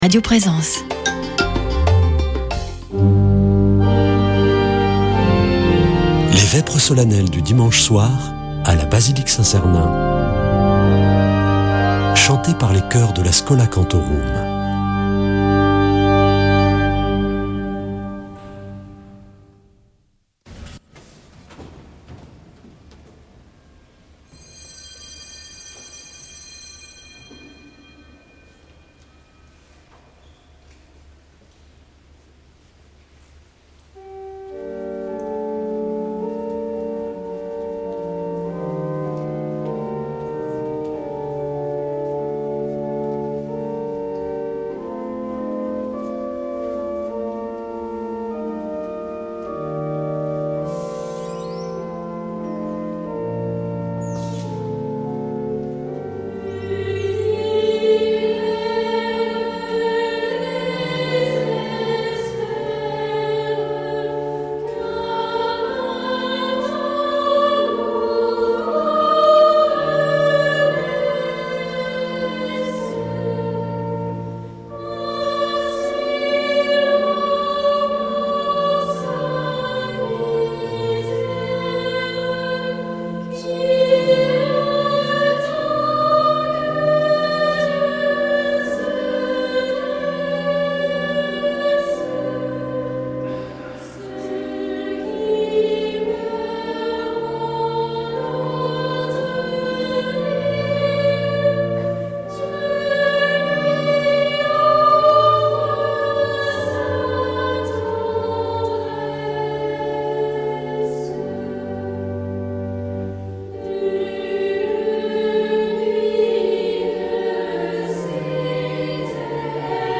Vêpres de Saint Sernin du 22 mars